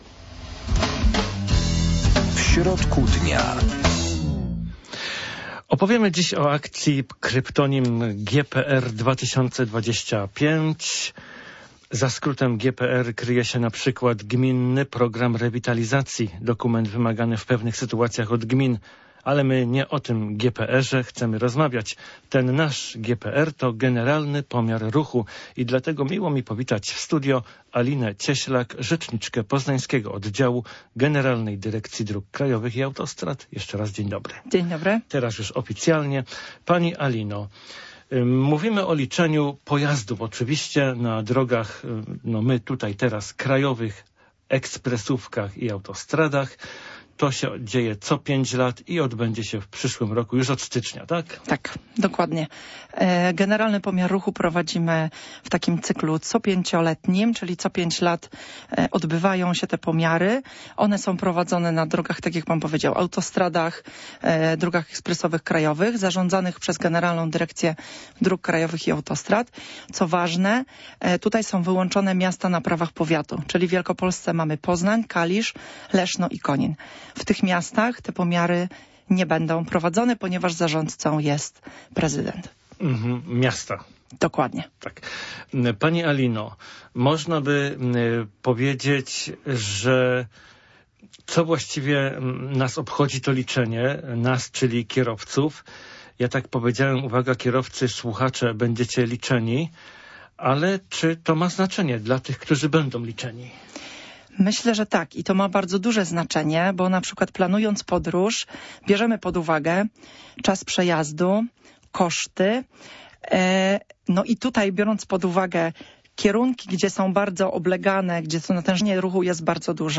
Również naszych Słuchaczy zapraszamy do rozmowy w audycji "W środku dnia"